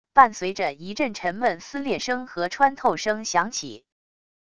伴随着一阵沉闷撕裂声和穿透声响起wav音频